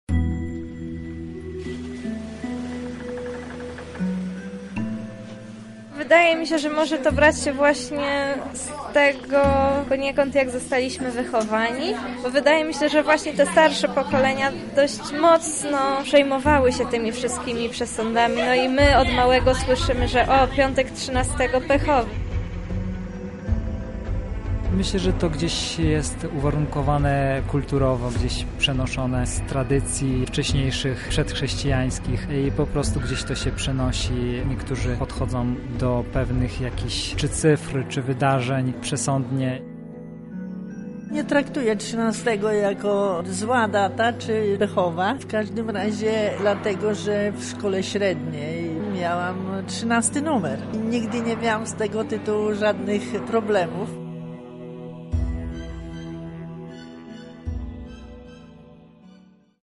Sprawdziliśmy, co na ten temat sądzą Lublinianie.